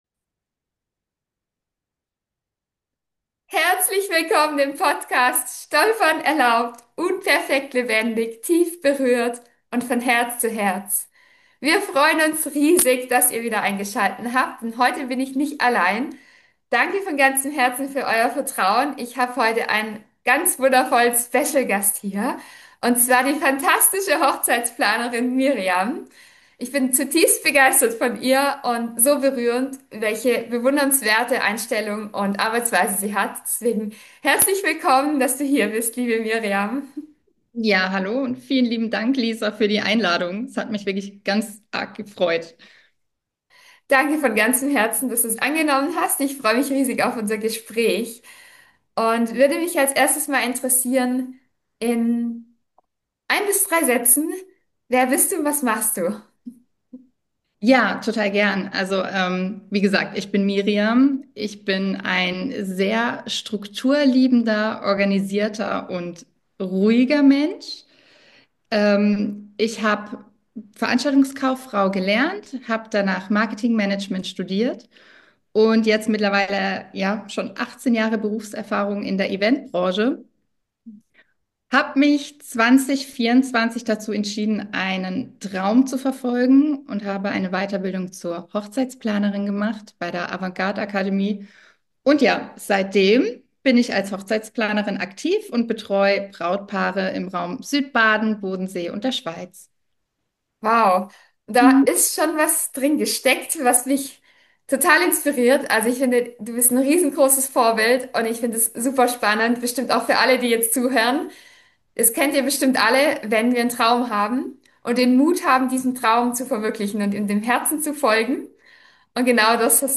Fühlt euch -lich Willkommen in dieser Interview-Folge!